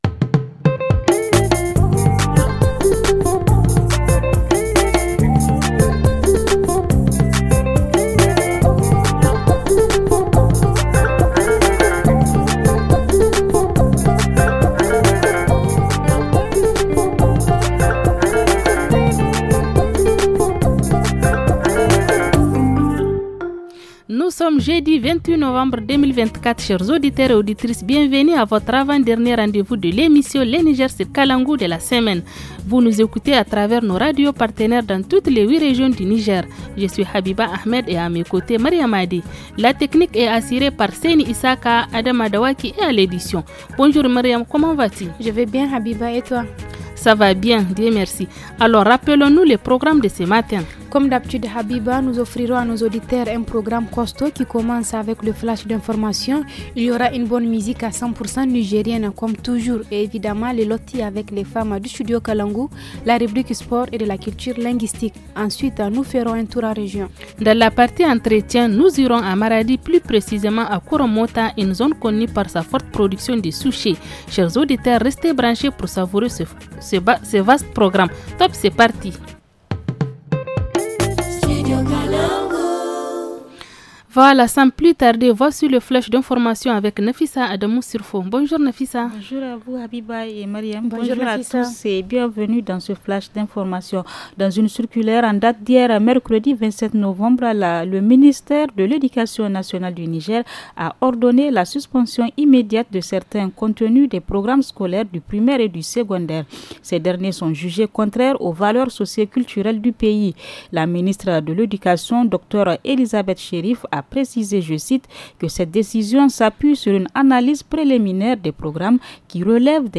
Entretien
Dans la rubrique hebdomadaire, focus sur l’héritage numérique. En reportage région, partons découvrir le groupement Nanaye de Balleyara, dans la région de Tillabéry.